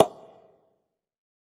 ECONGAS.wav